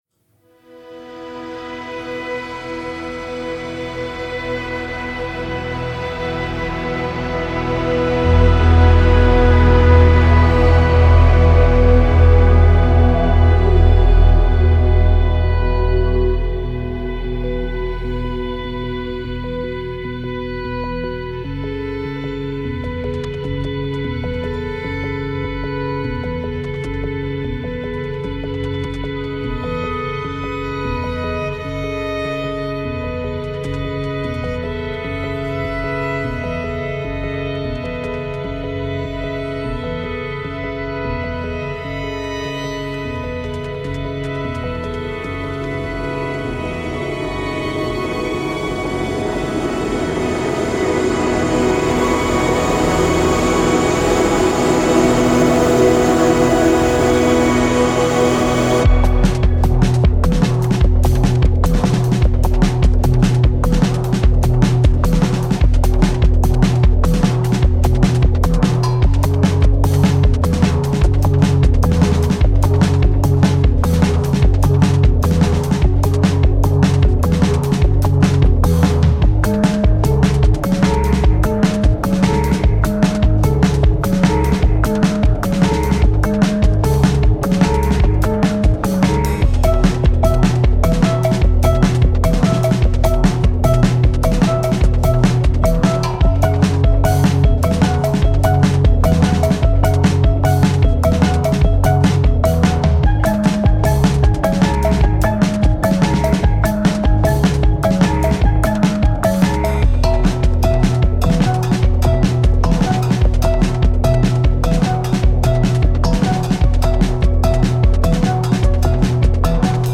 Violinist
drummer/electronic shapeshifter
the striking and stirring